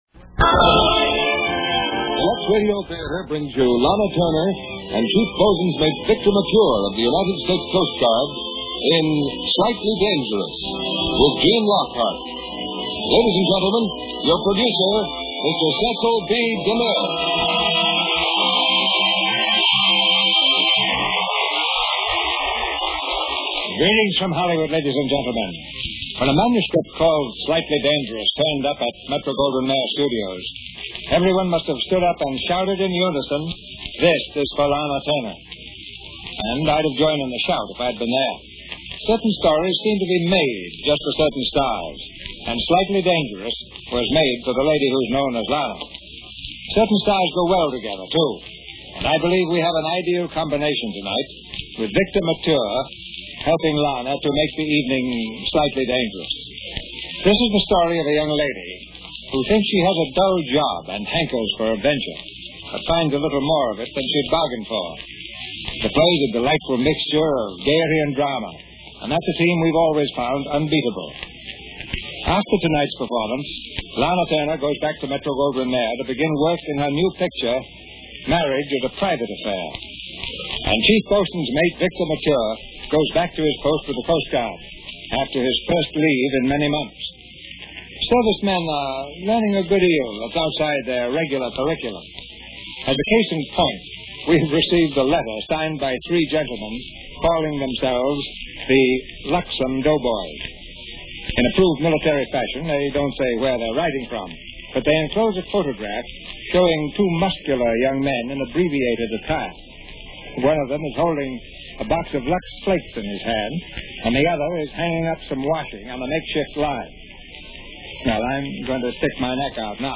starring Lana Turner, Victor Mature
Lux Radio Theater Radio Show